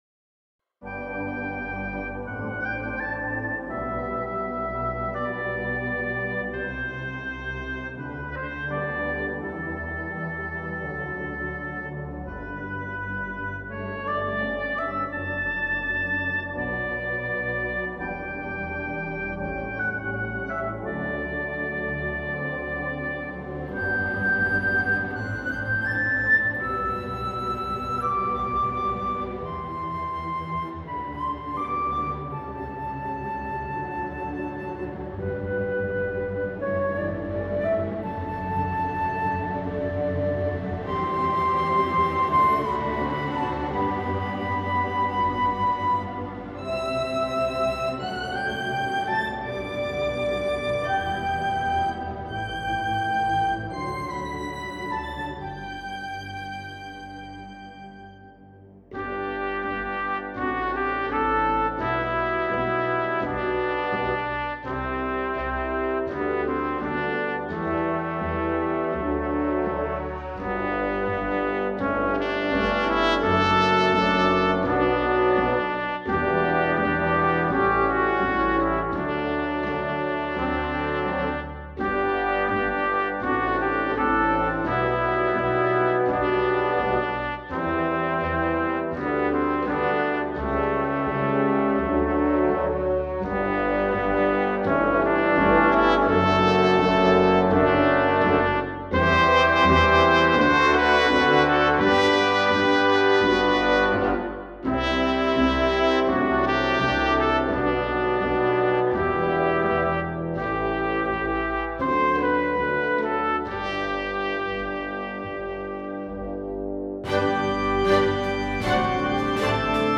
all created with samples
Most of the pieces are played between 2002 and 2014 and also mixed as it was normal at that time...